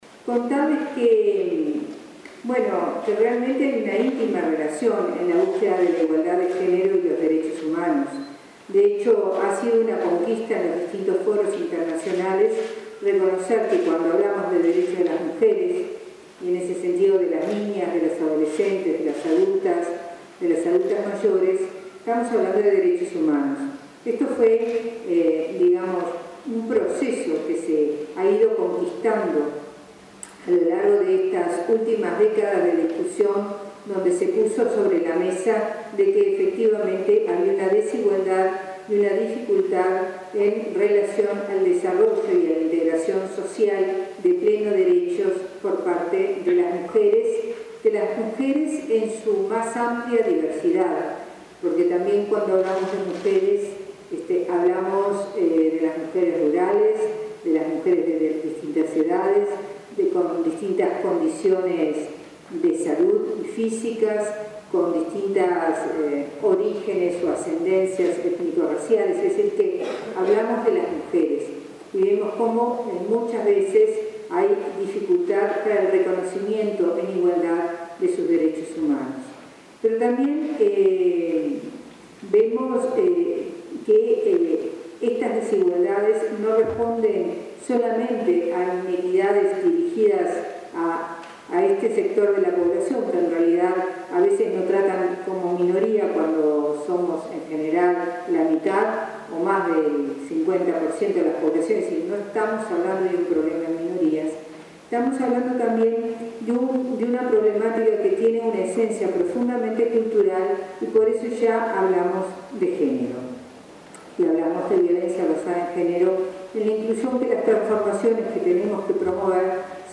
La directora de Inmujeres, Mariela Mazzoti, sostuvo que hay una íntima relación entre derechos de las mujeres y derechos humanos. Mazzotti disertó en un seminario sobre cambio cultural, derechos humanos y violencia basada en género, realizado en el teatro Solís. En ese marco, se refirió a la importancia del deporte, y, en especial, el fútbol infantil, para trasmitir mensajes de igualdad de género así como el ámbito cultural.